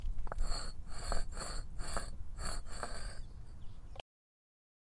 OWI的拖曳声
描述：砖块相互刮擦。
Tag: 格栅 格栅 搔抓 摩擦 MOV E 划伤